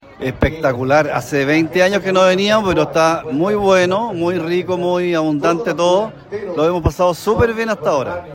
uno de los asistentes